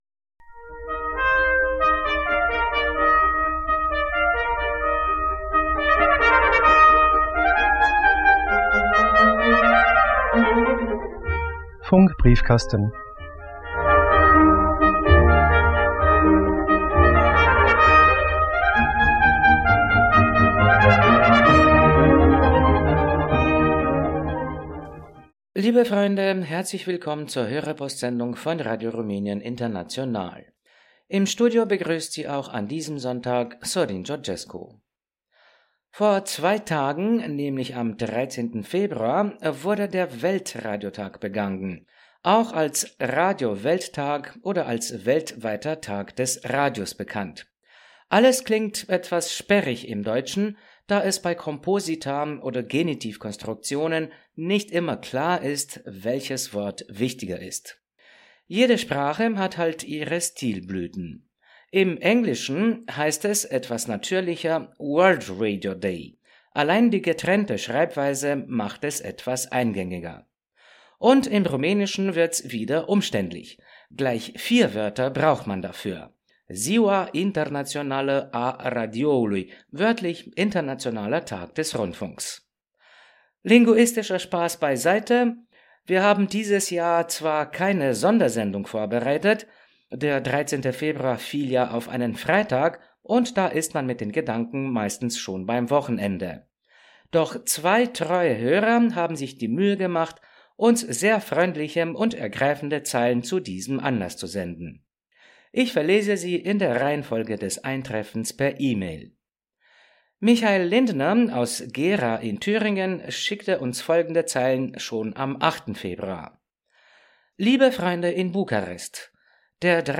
Hörerpostsendung 15.02.2026